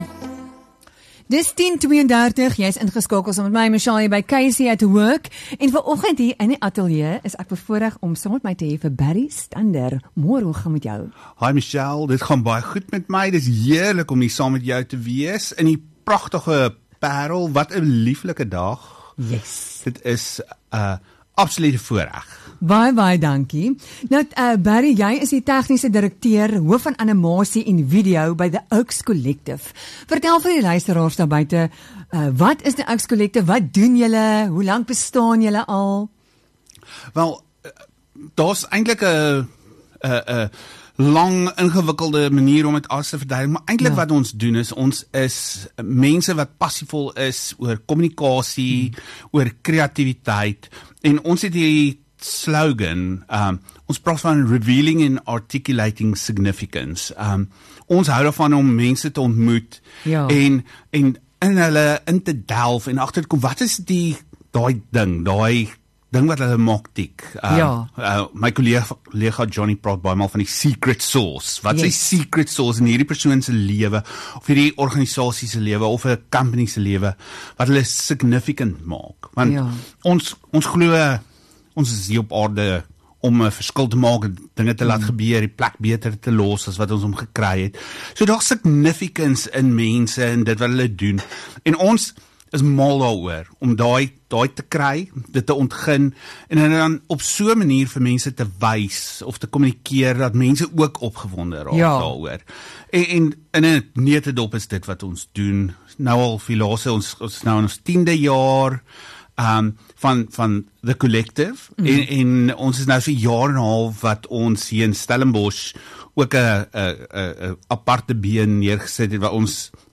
Onderhoud